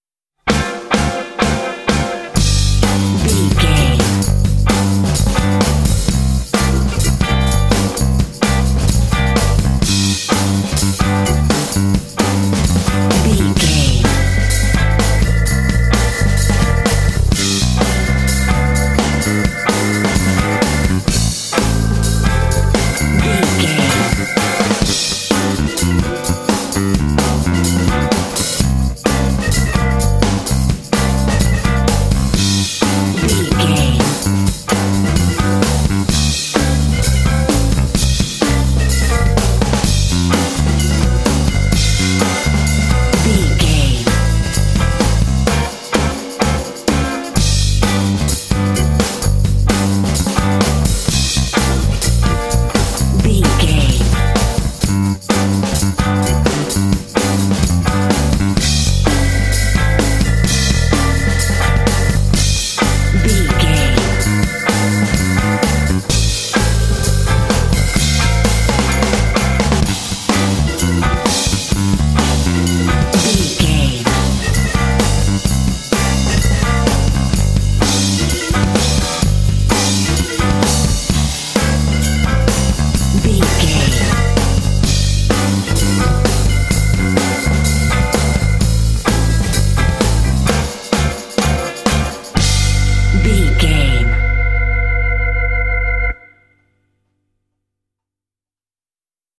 Aeolian/Minor
intense
driving
energetic
groovy
bass guitar
electric guitar
drums
electric organ
Funk
blues